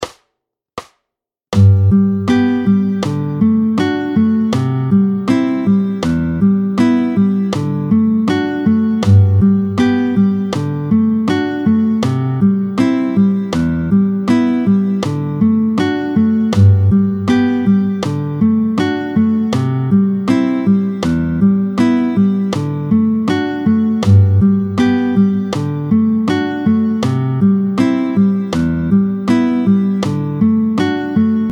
07-02 La mesure à 2/4 (2 temps), tempo 80